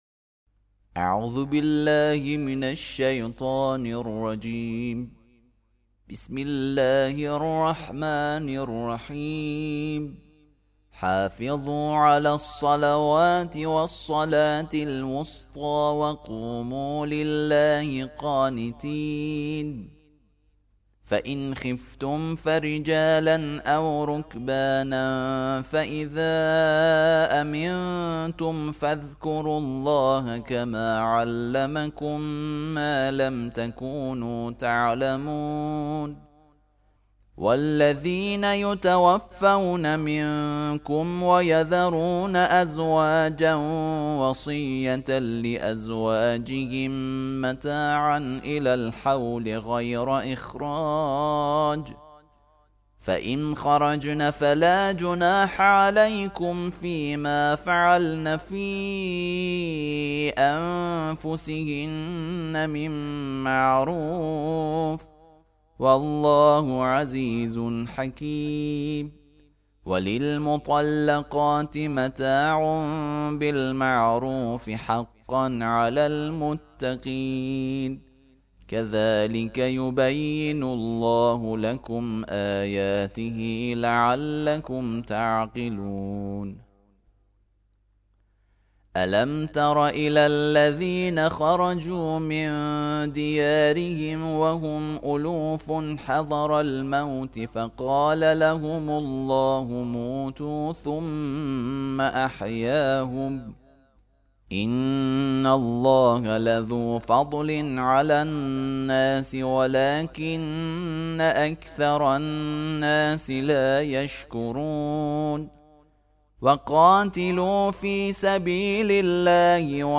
ترتیل صفحه ۳۹ سوره مبارکه بقره (جزء دوم)
ترتیل سوره(بقره)